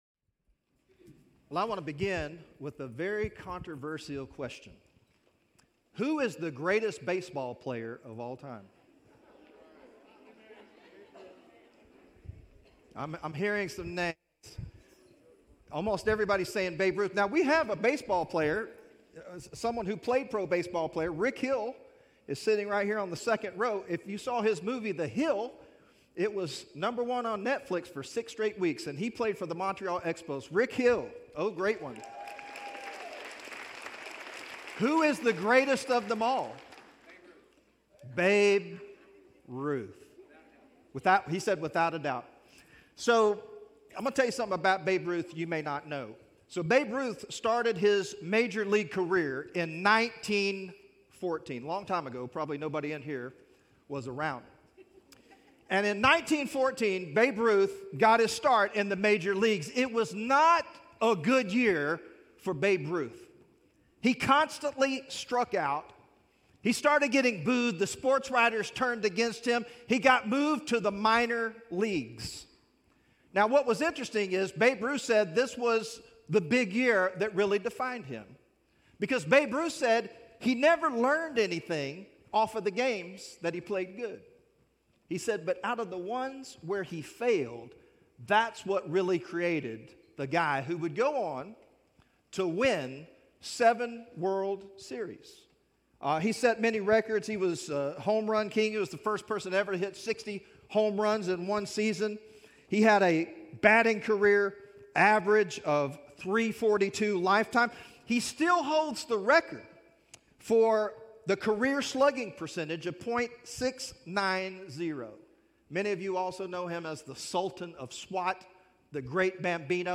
From Series: "Guest Speaker"
Sermon Listen Worship The story of Peter's fishing experience in Luke 5 reveals how God transforms failure into success.